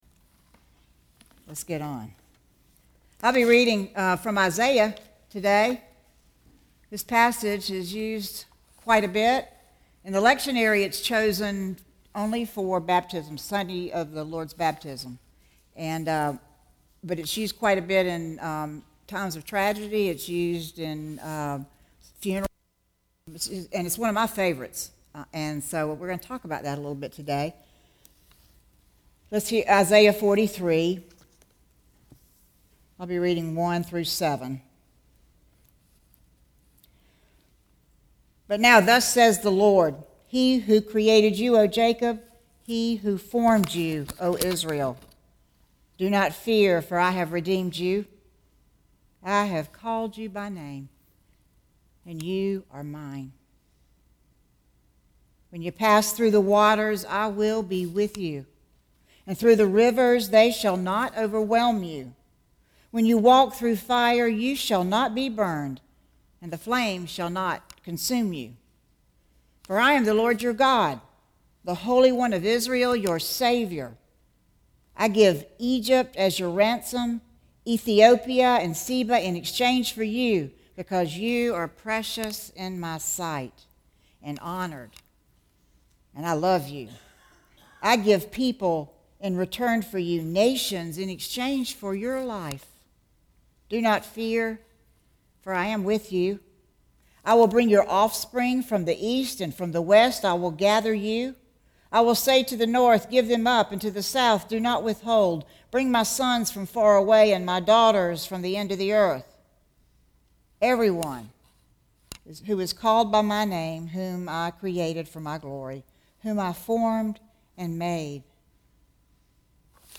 Sermons | St. Andrew United Methodist Church